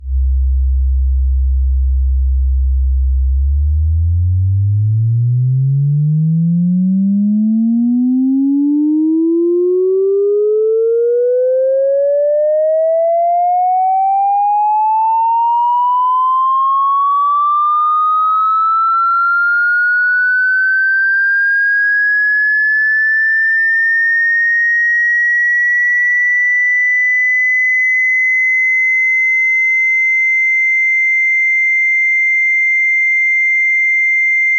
Here are the frequency sweeps using the Prologue’s own digital sine output (from the VPM) and a sawtooth from an analogue VCO:
prologue-sine.wav